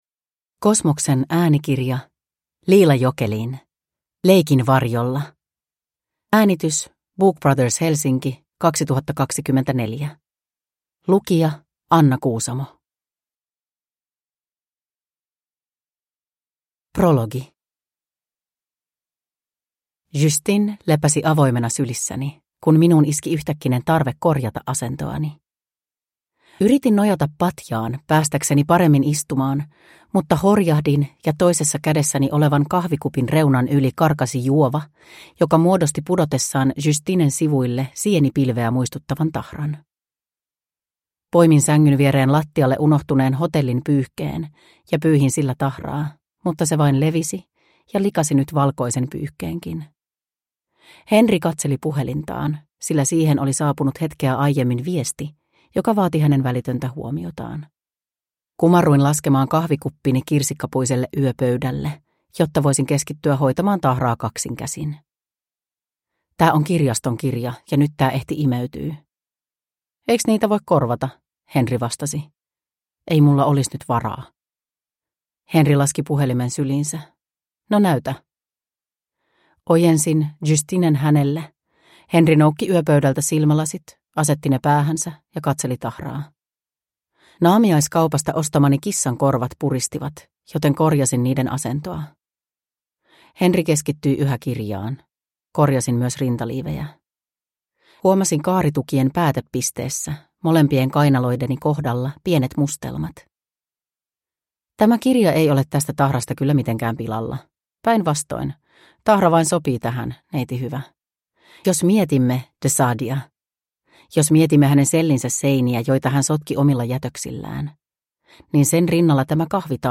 Leikin varjolla – Ljudbok